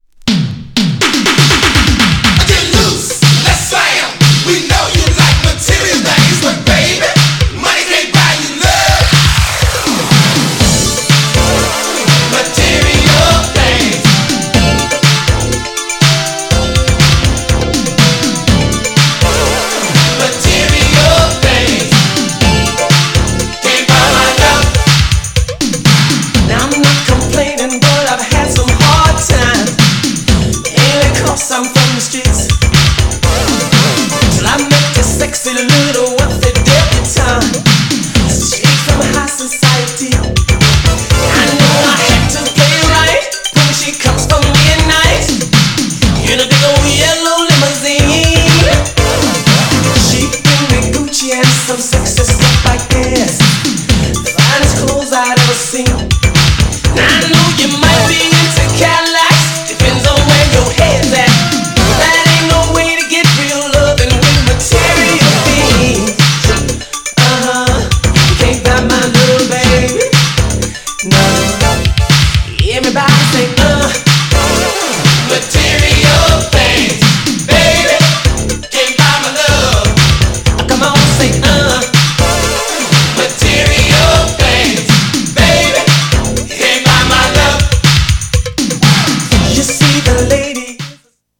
GENRE Dance Classic
BPM 111〜115BPM